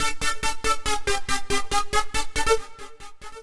Index of /musicradar/future-rave-samples/140bpm
FR_Arpune_140-A.wav